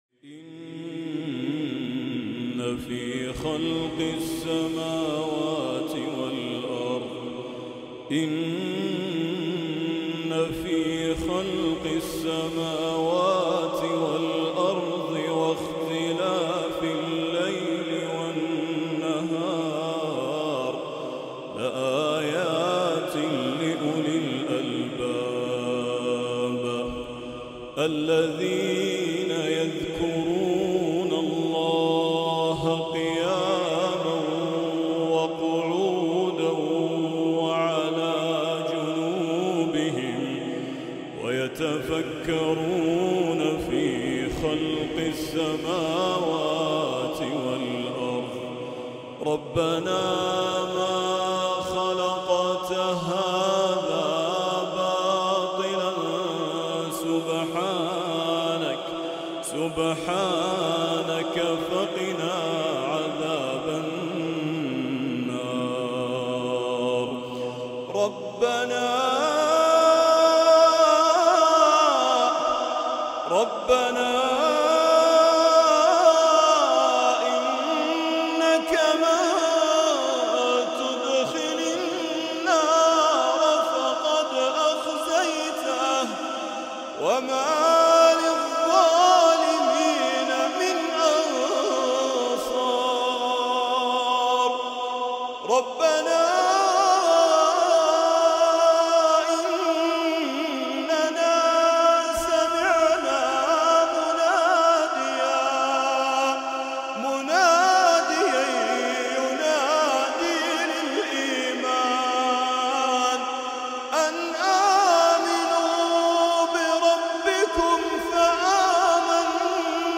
تلاوه خاشعه وصوت جميل